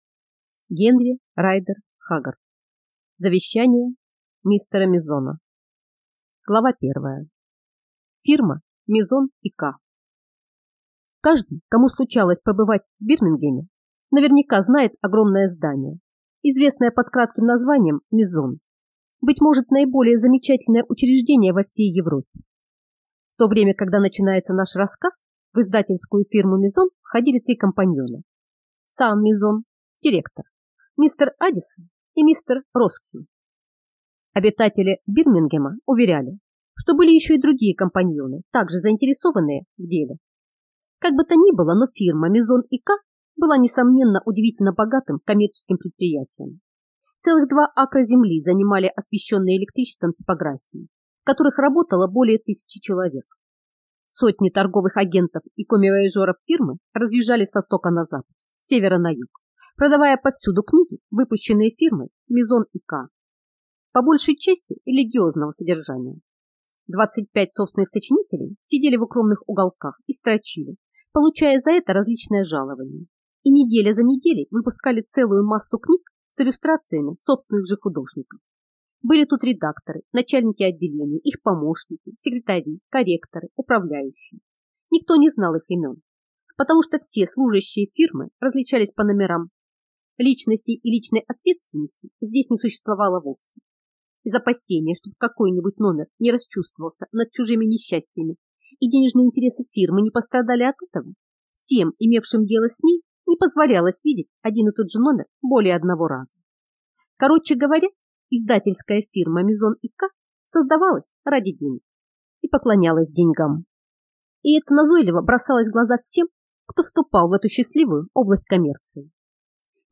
Аудиокнига Завещание мистера Мизона | Библиотека аудиокниг